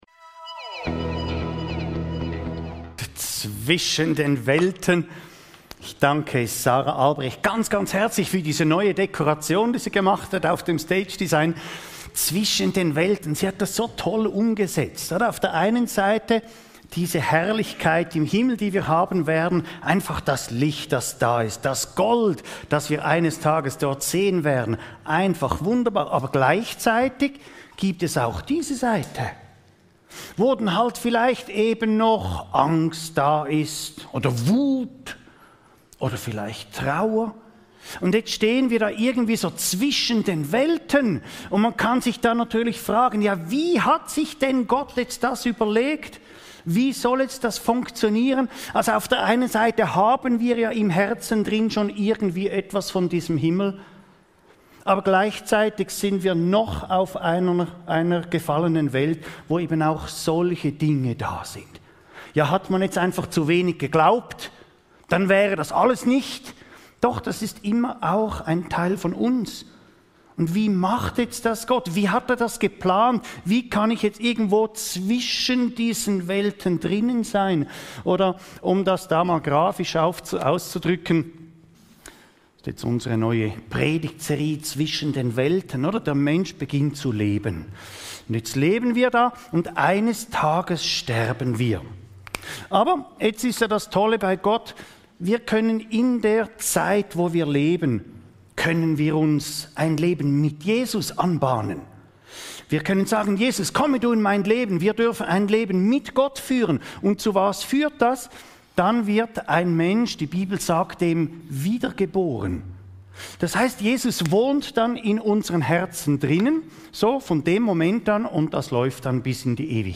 Wie hat sich Gott das überlegt? (Joh 15,1-17) ~ Your Weekly Bible Study (Predigten) Podcast